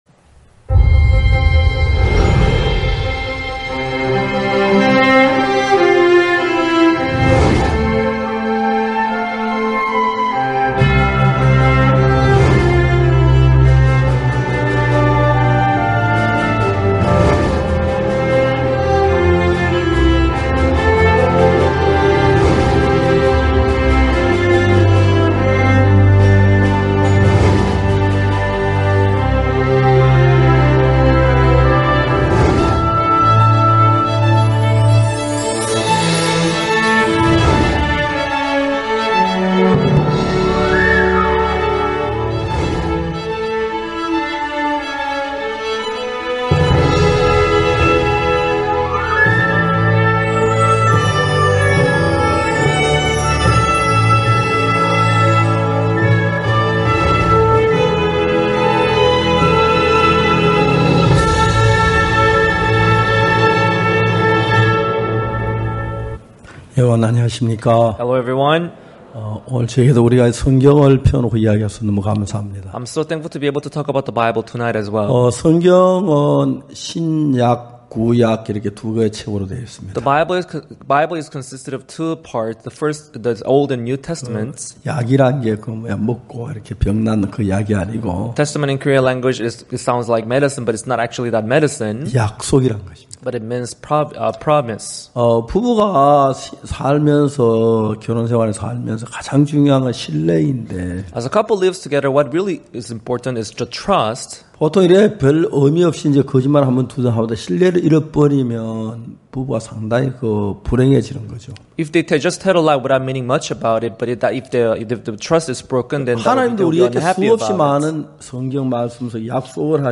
전국 각 지역의 성도들이 모여 함께 말씀을 듣고 교제를 나누는 연합예배.